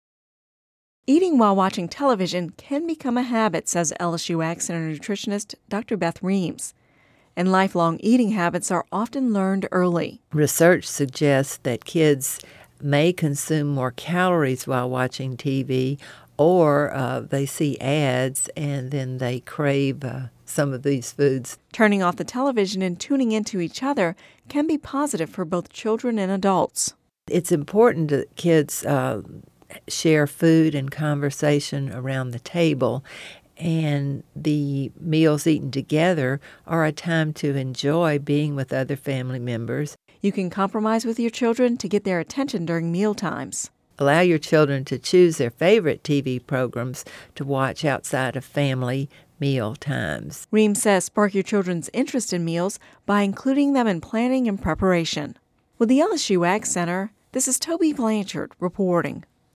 (Radio News 09/20/10) Eating while watching television can become a habit and lead to unhealthful eating habits